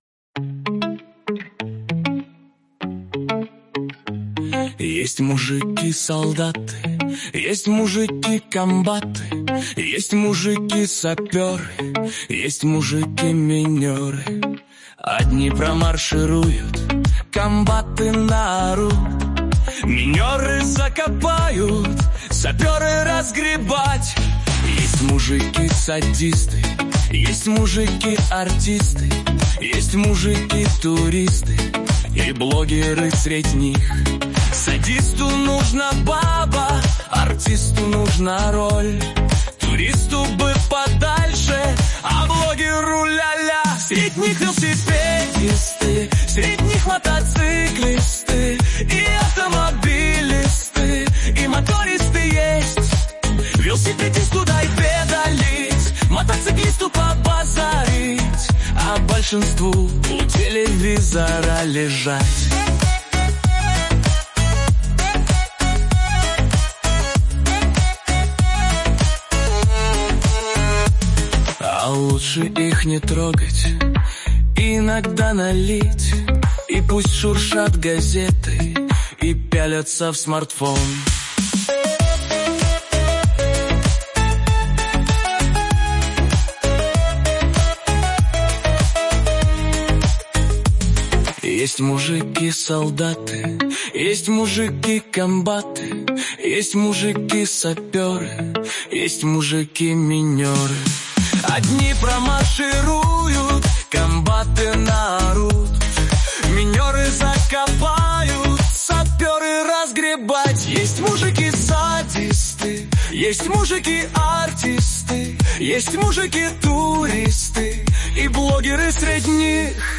Правда я этот датай сжал, но слушать можно.